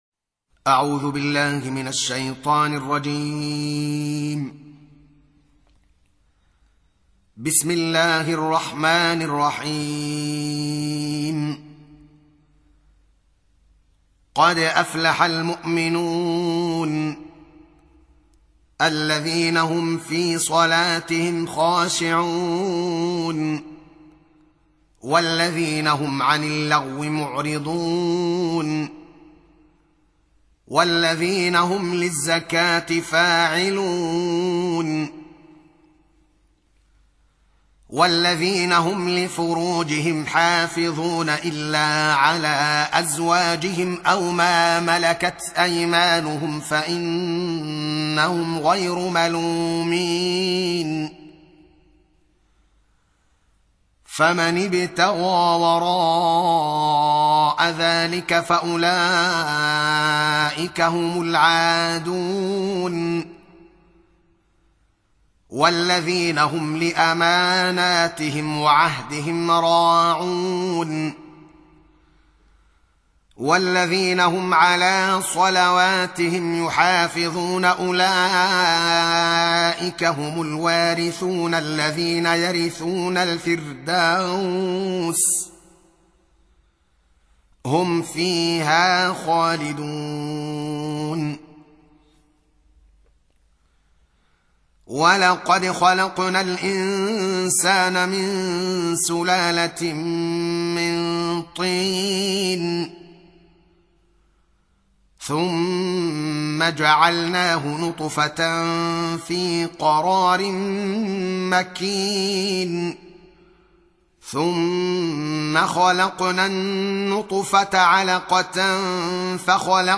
Surah Repeating تكرار السورة Download Surah حمّل السورة Reciting Murattalah Audio for 23. Surah Al-Mu'min�n سورة المؤمنون N.B *Surah Includes Al-Basmalah Reciters Sequents تتابع التلاوات Reciters Repeats تكرار التلاوات